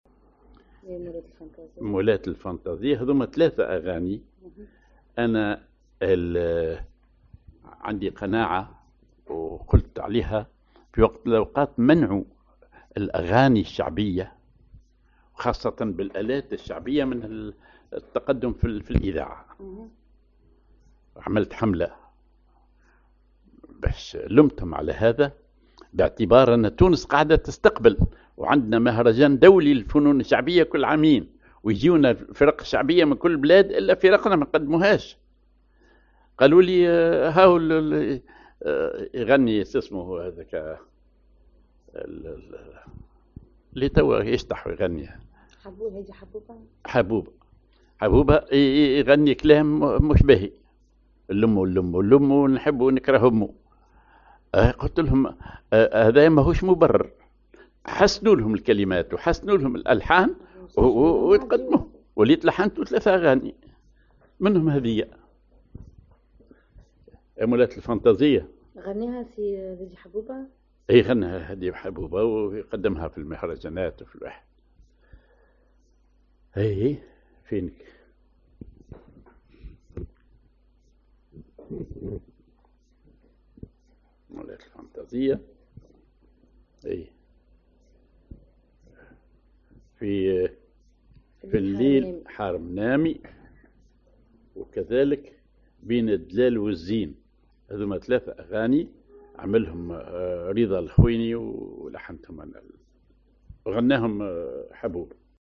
Maqam ar رصد الذيل
Rhythm ar فزاني وغيطة
genre أغنية